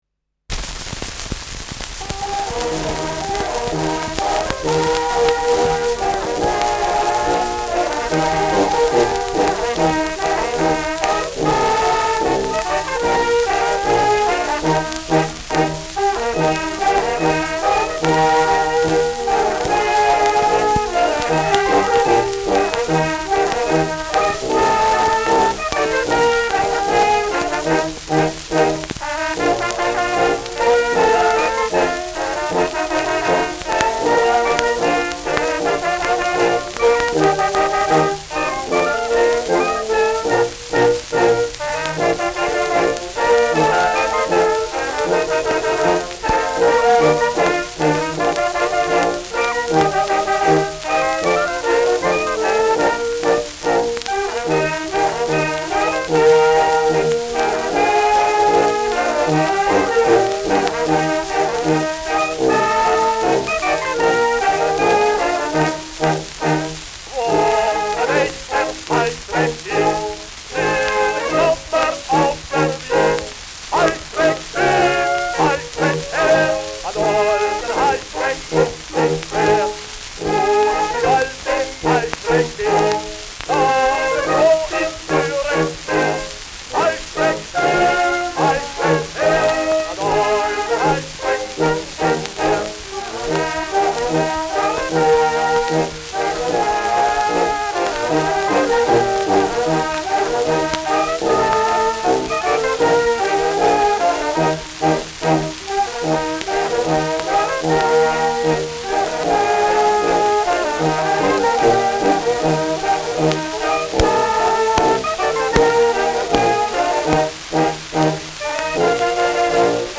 Schellackplatte
präsentes Rauschen : präsentes Knistern : abgespielt : leiert : gelegentliches „Schnarren“ : häufiges Knacken